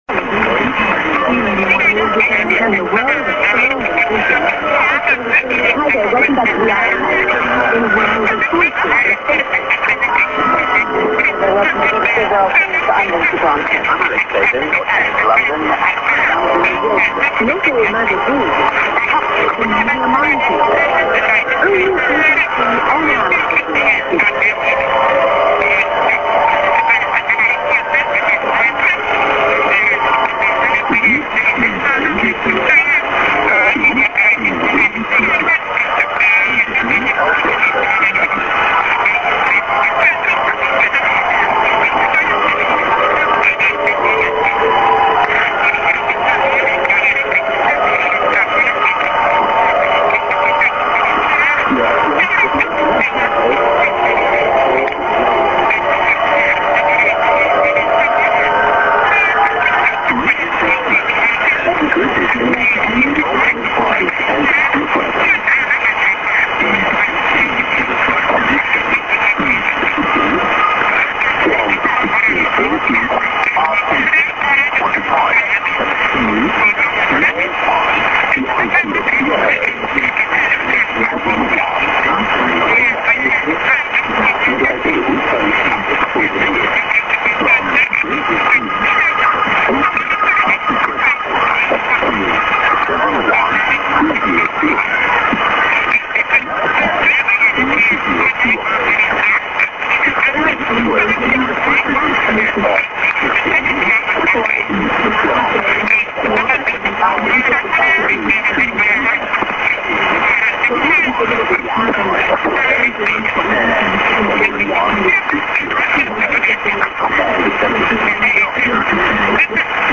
St. IS->ID:"This is R.Tiranax2+SKJ(man)->　＊back(QRM) TWR Africa 29'30":BBC s/off